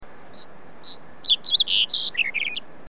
bird